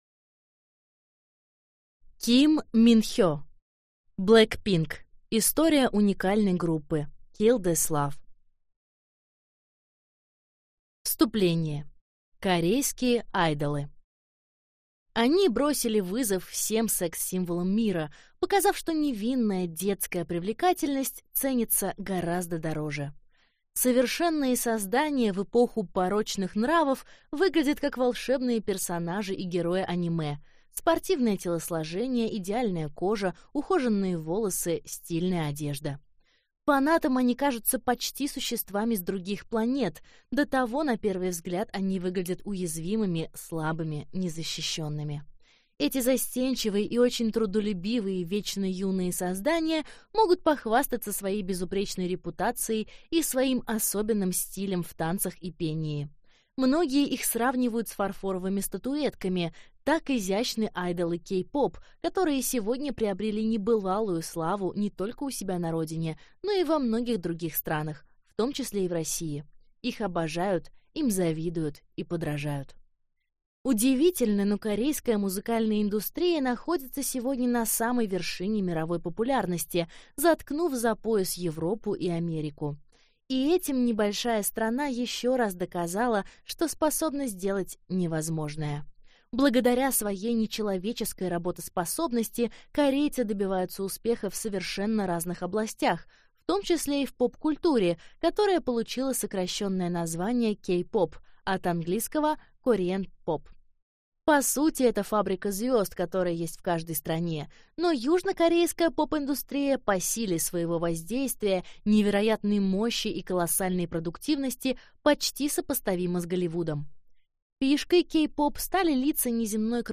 Аудиокнига Blackpink. История уникальной группы. Kill this love | Библиотека аудиокниг